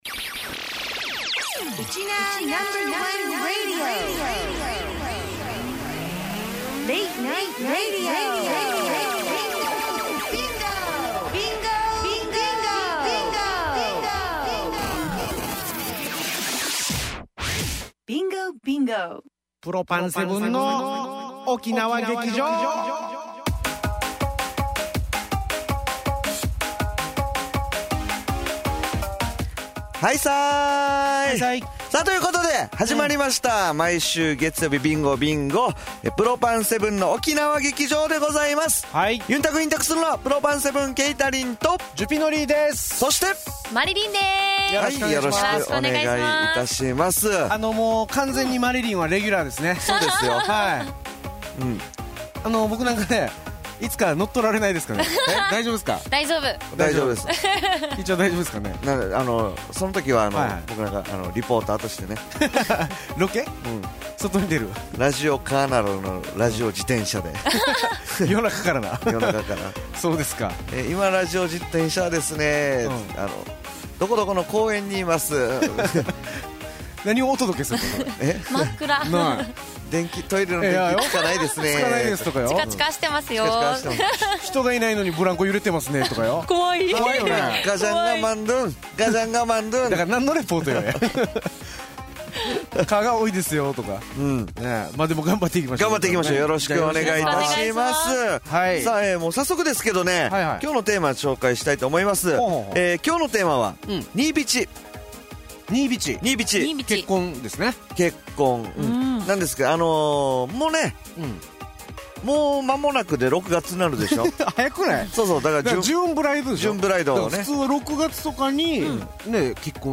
暫く待っても再生出来ない時はもう一度ＰＬＡＹボタンを押して下さい プロパン７の沖縄劇場をitunesに登録 USTREAM動画 こちらの再生ボタンを押して下さい 番組紹介 沖縄のお笑いコンビ